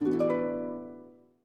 HarpUnlock.ogg